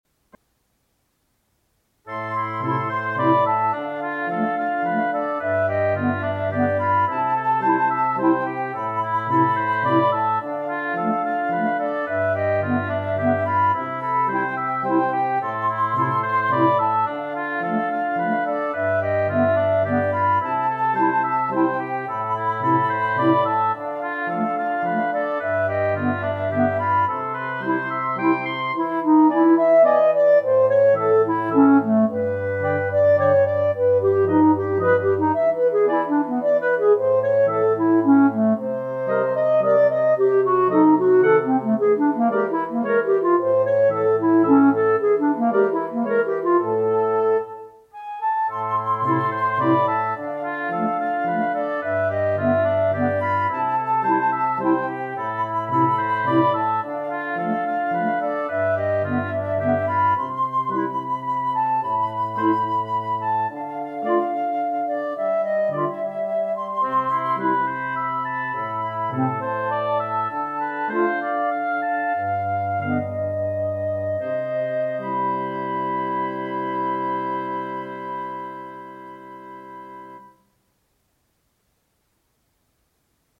Wind Quintet
Instrumentation: Flute, Oboe, Clarinet, Horn in F, Bassoon